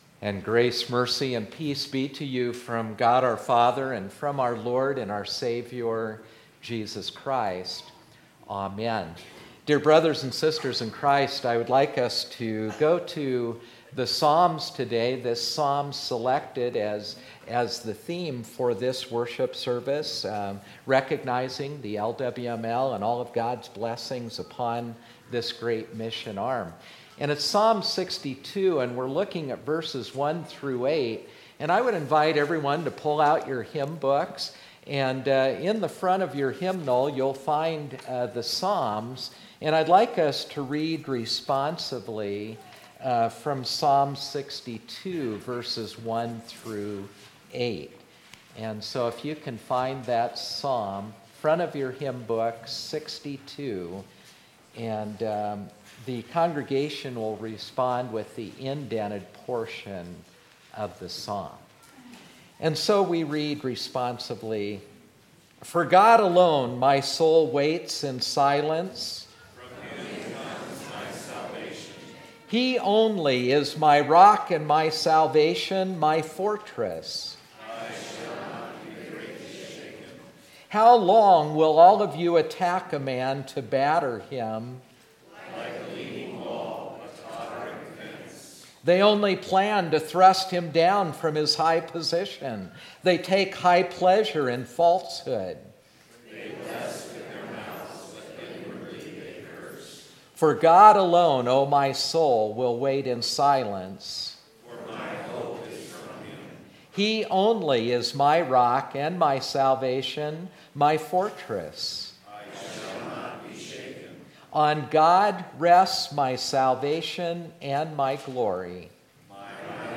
Sermon 2018-10-07